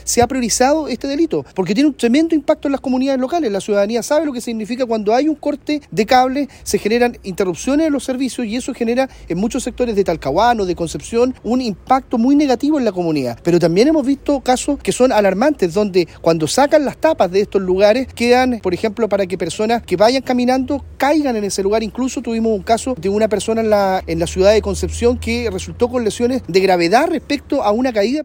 El delegado presidencial del Bío Bío, Eduardo Pacheco, dijo que este delito afecta directamente a la ciudadanía, recordando el caso de una mujer que resultó con graves lesiones tras caer a una cámara que había sido robada.
delegado-robo-cables.mp3